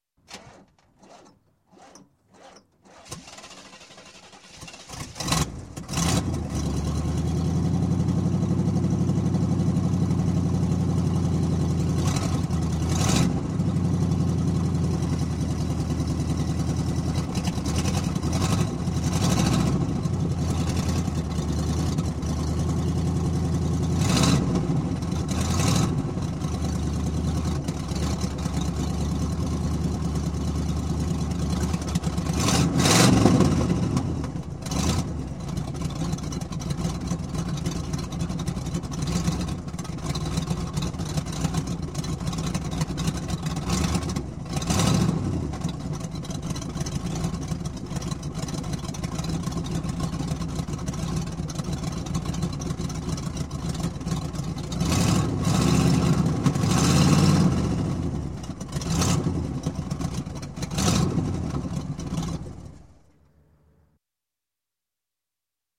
Звуки выхлопа машин
Хриплый звук выхлопа старенькой машины